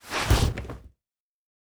Foley Sports / Skateboard / Bail C.wav